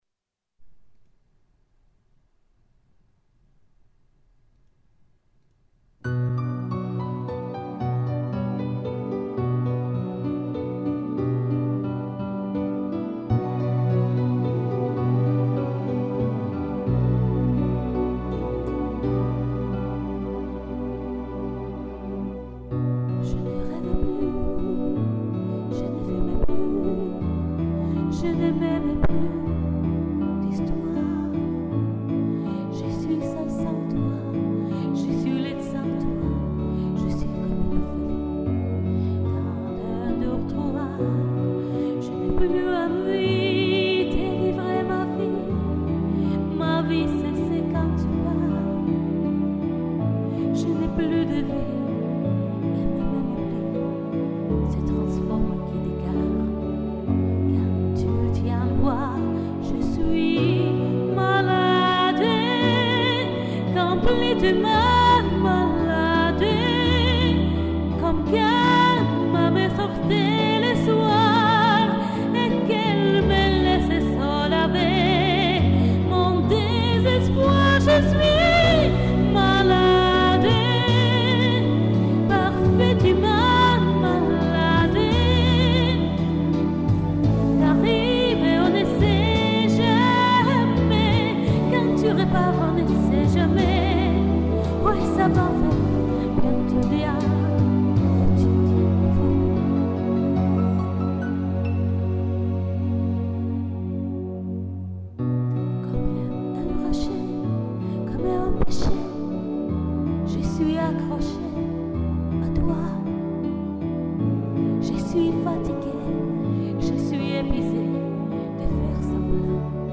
запись не очень удачная... в некоторых местах очень тихо